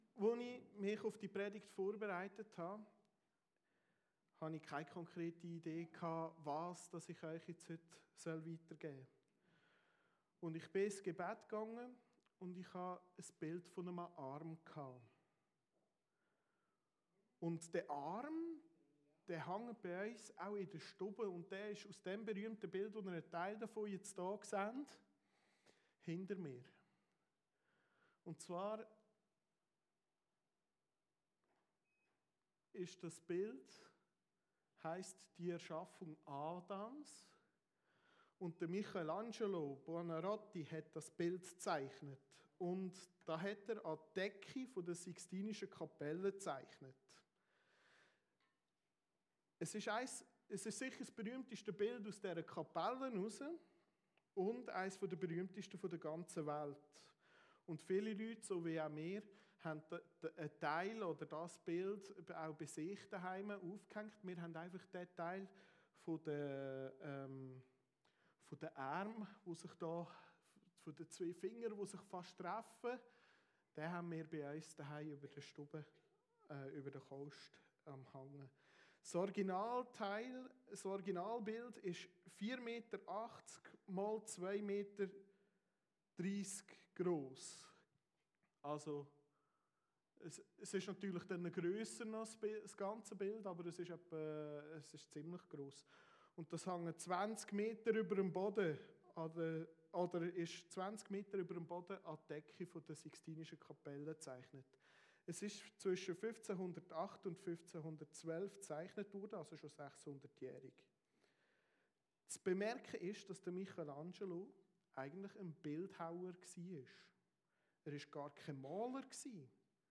Predigten Heilsarmee Aargau Süd – Sehnsucht Gottes nach uns